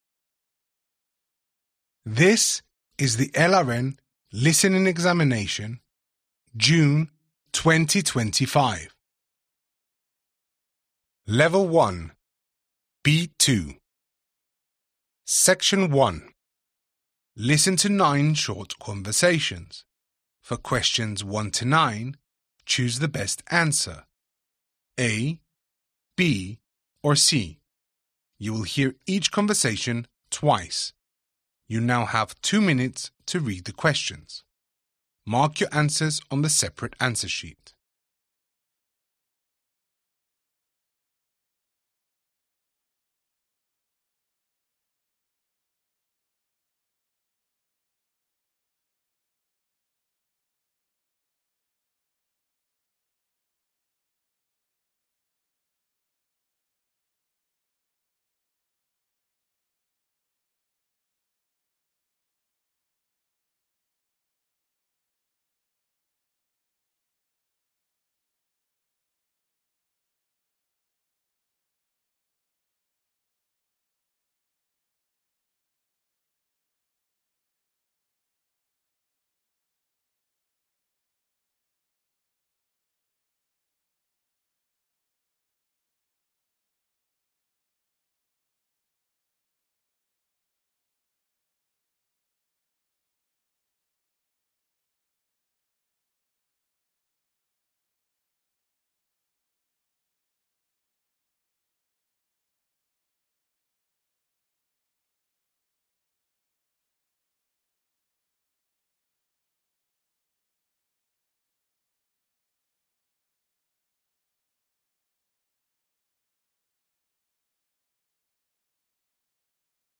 Listen to 9 short conversations.
You will hear each conversation TWICE.